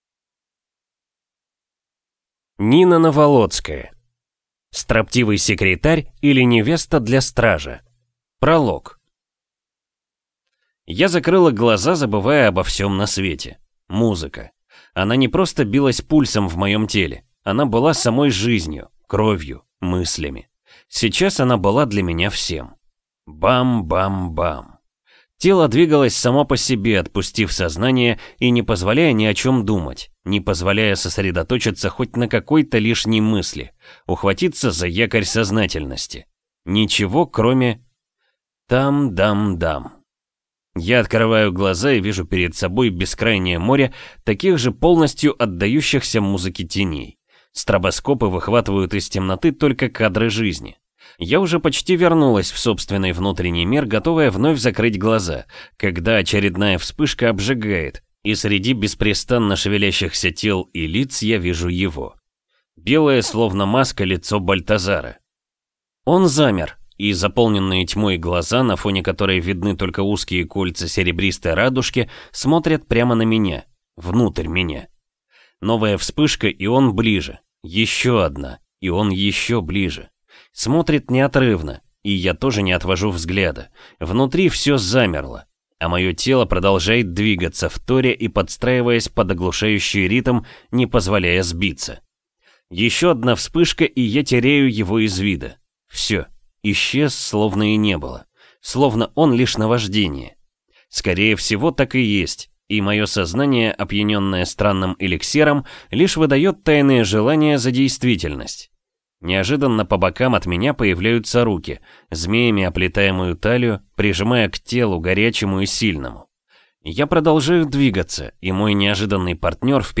Аудиокнига Строптивый секретарь, или Невеста для cтража | Библиотека аудиокниг